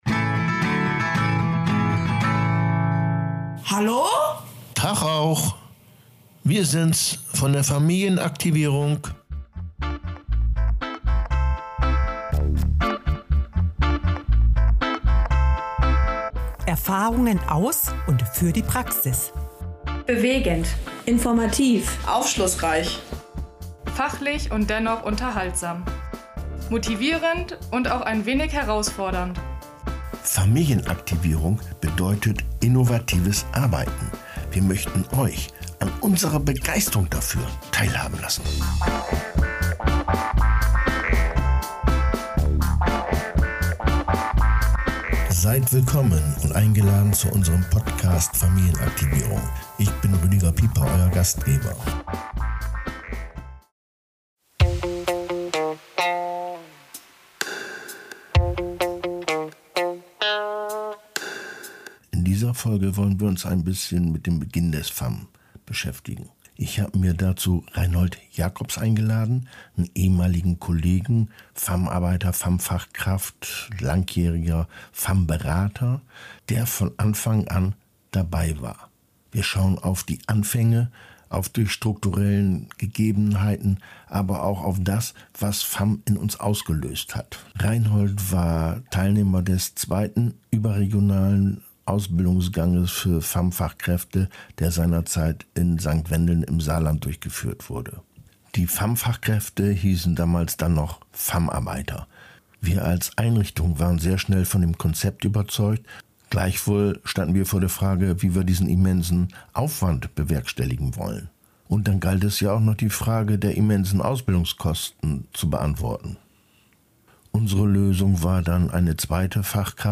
Ein Gespräch von Zweien, die von Beginn an dabei waren.